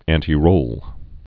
(ăntē-rōl, ăntī-)